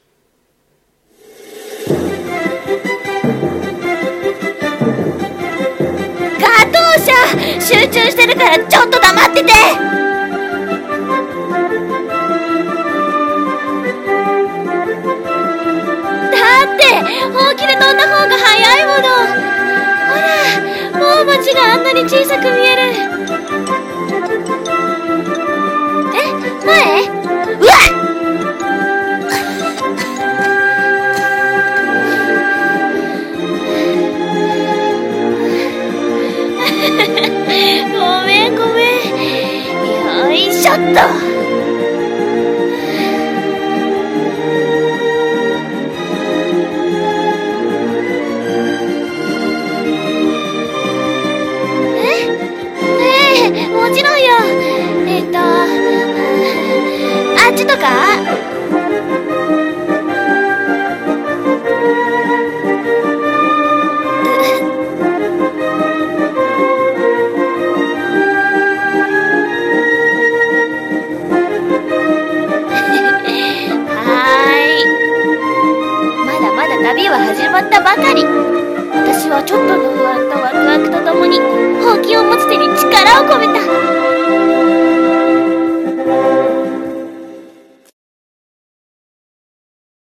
【声劇】魔女と猫と遥かなる空